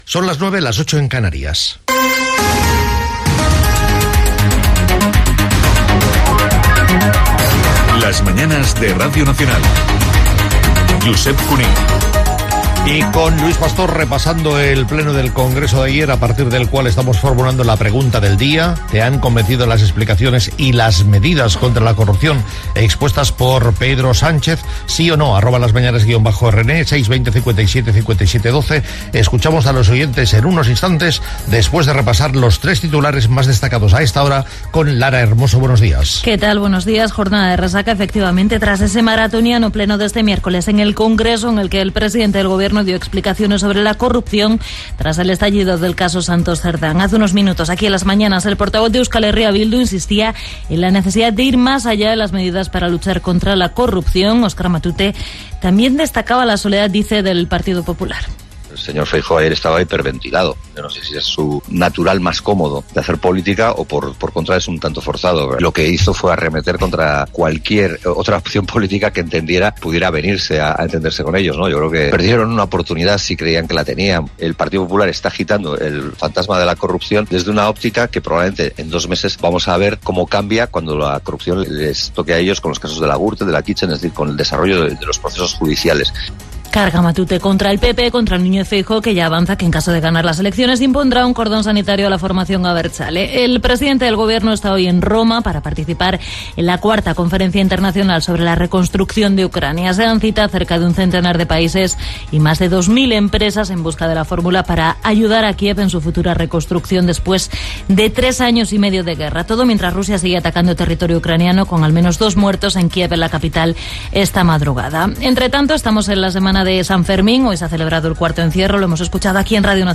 Hora, indicatiu del programa, tres titulars del dia, ferits a l'"encierro de Pamplona", promoció de "Tablero deportivo", indicatiu, opinió de l'audiència, valoració del debat del dia anterior al Parlament espanyol sobre la corrupció política Gènere radiofònic Info-entreteniment